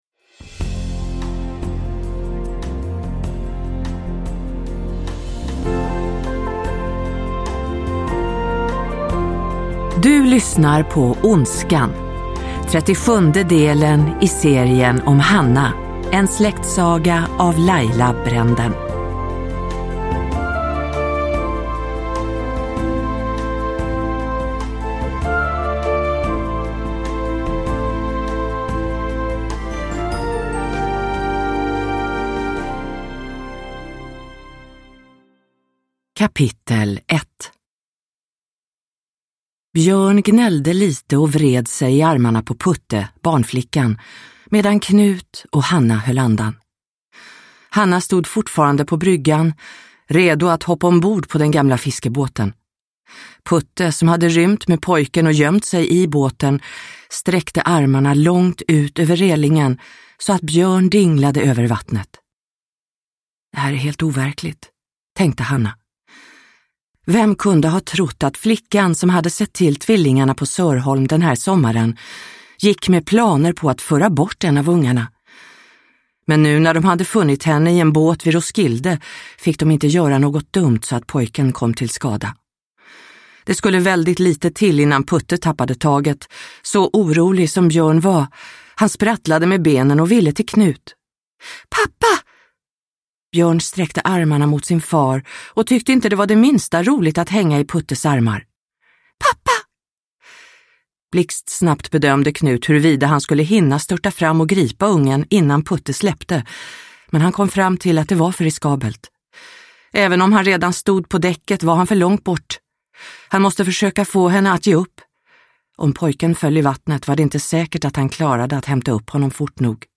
Ondskan – Ljudbok – Laddas ner